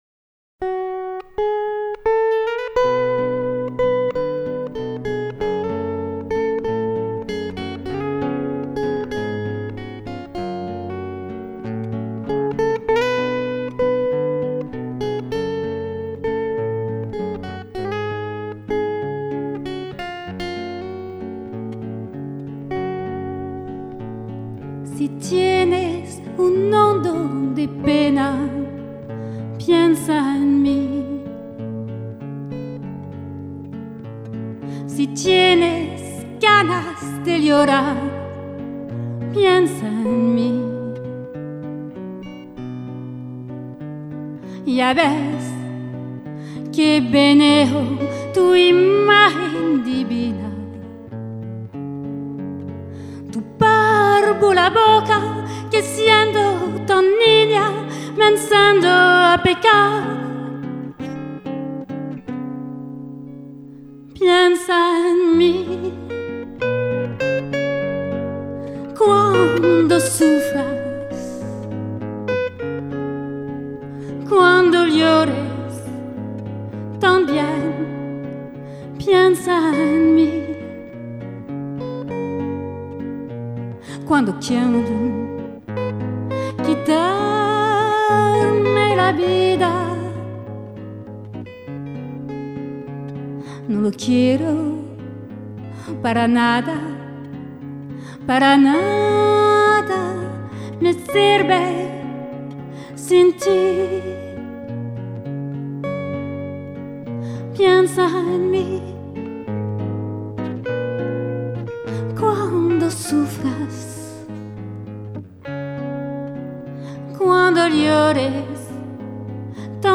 un moment de grâce dans notre studio
Masterisation maison